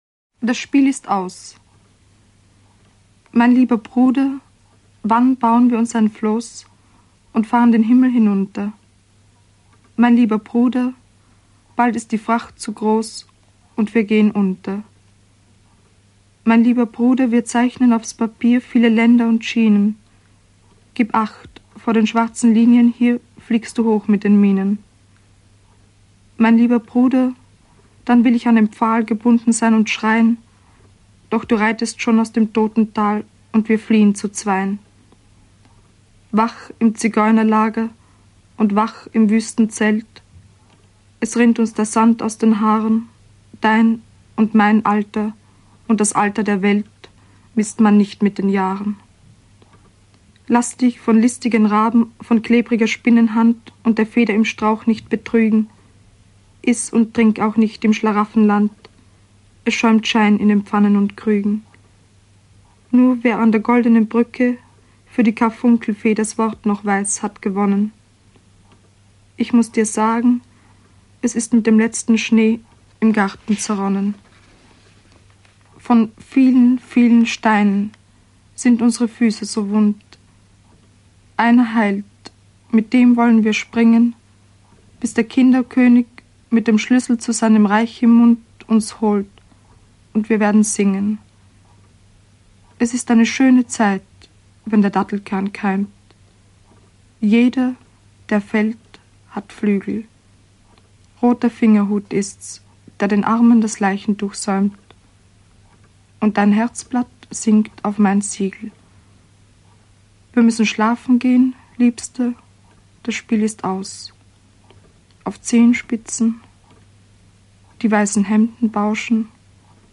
Ingeborg Bachmann (Sprecher)
Ingeborg Bachmann ist in ihrem unverwechselbar brüchigen Ton zu hören, der ihre Lesungen zum Mythos machte.